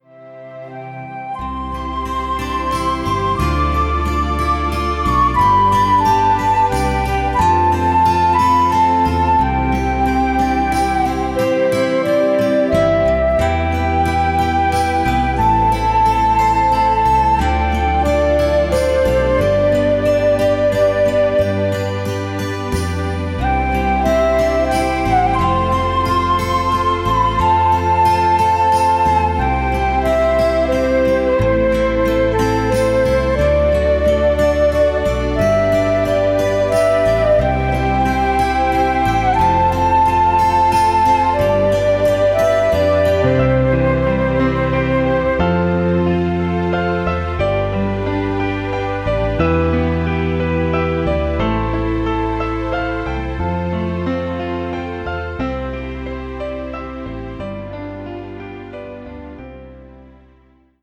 Naturgeräusche sind auch mit an Bord.